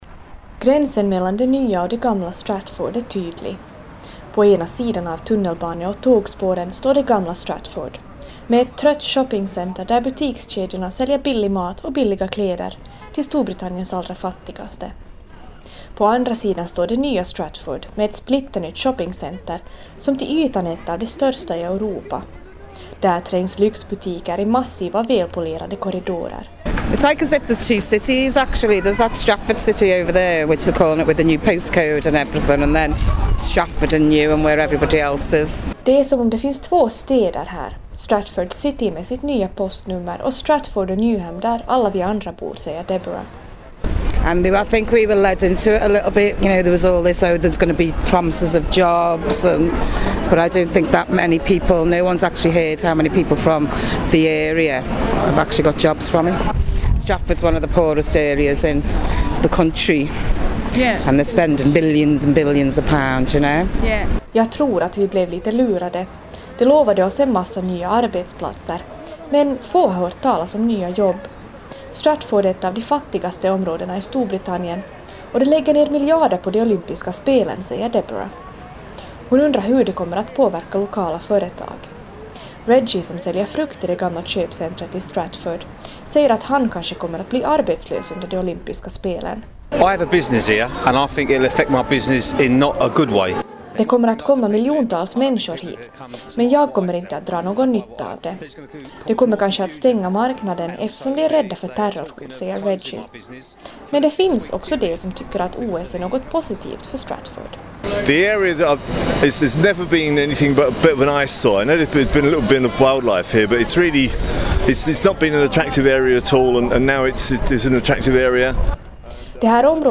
Intervju med Yleisradio/Rundradion